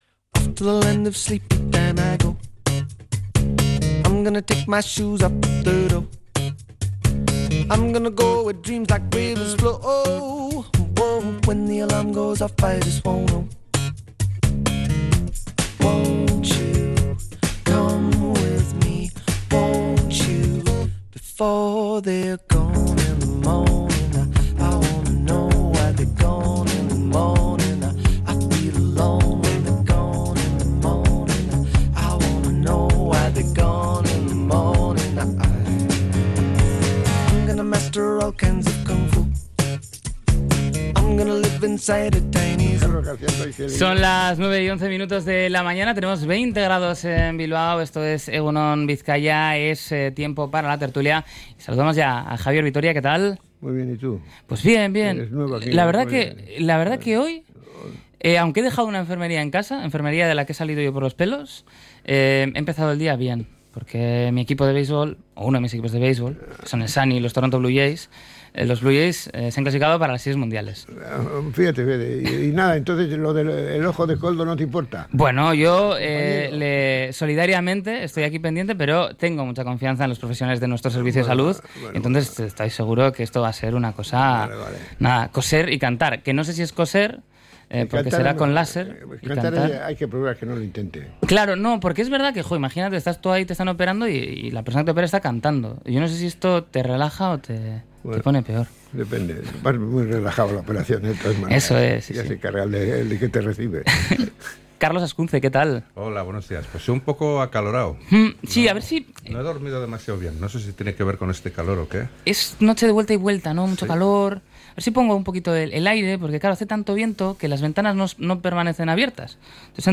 La Tertulia 21-10-25.